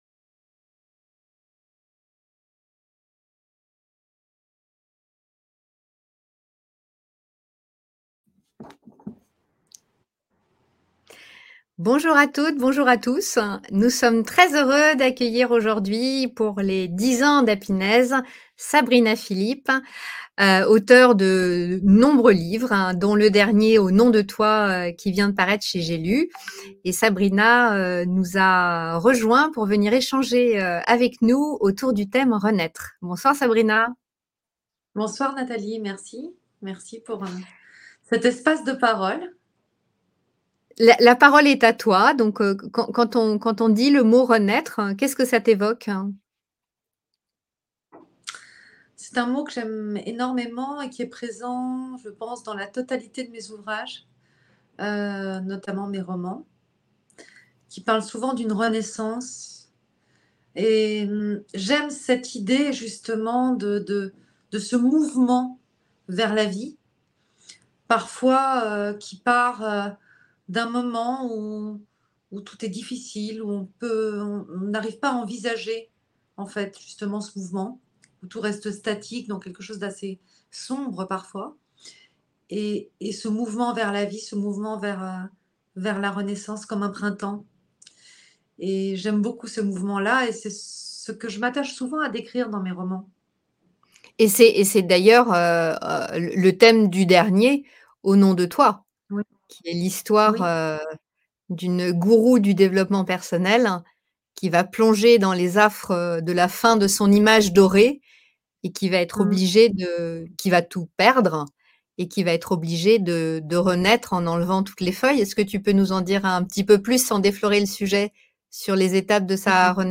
Interview 10 ans - Renaître